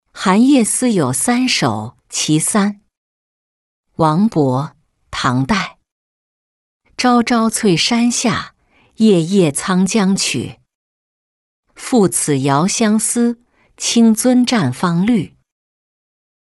欹湖-音频朗读